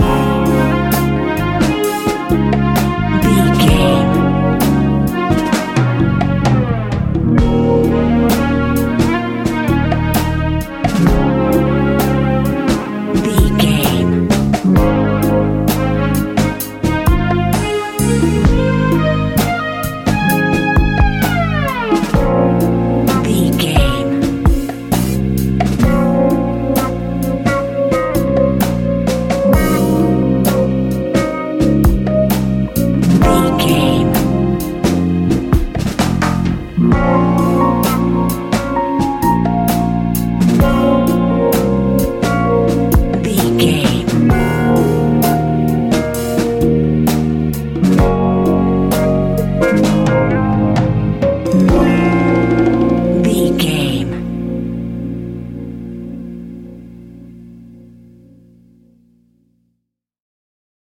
Fast paced
Uplifting
Ionian/Major
A♯
hip hop
instrumentals